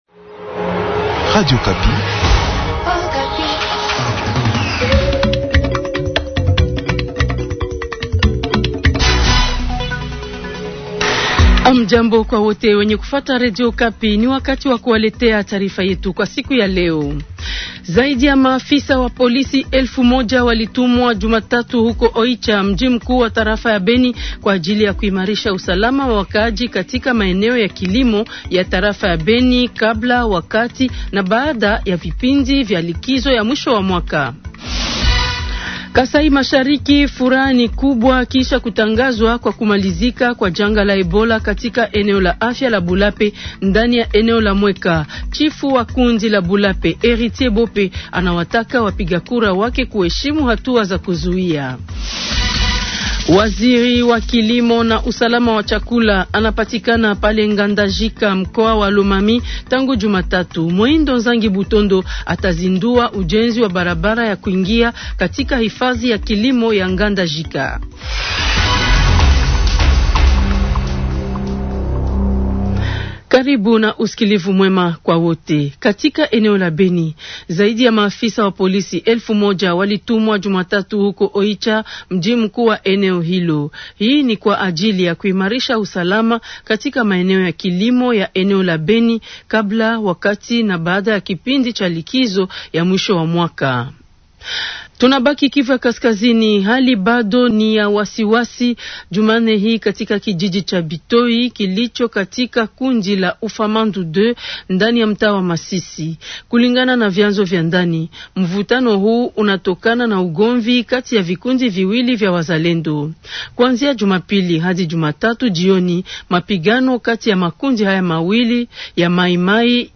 Ripoti Maalum Katika jimbo la Maniema, kampeni ya kutunza fistula kwenye Hospitali Kuu ya Rufaa ya Kindu inaendelea vizuri.